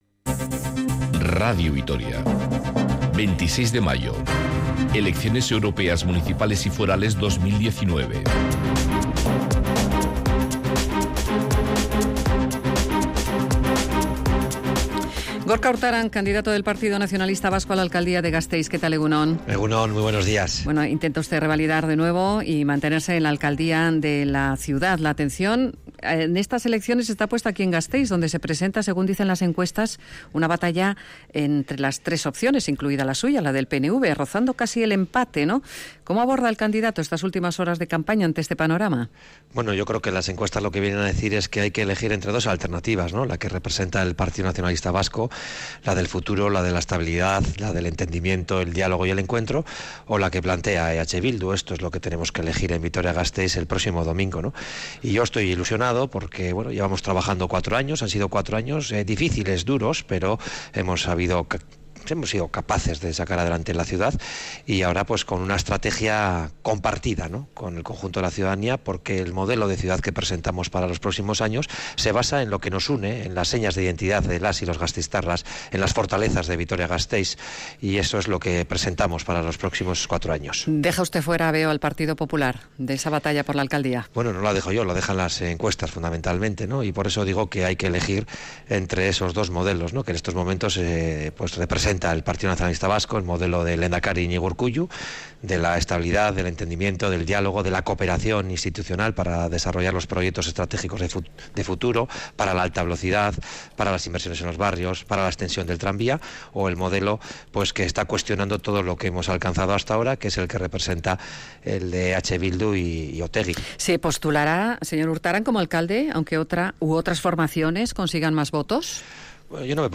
Entrevista con Gorka Urtaran, candidato del PNV a la alcaldía de Gasteiz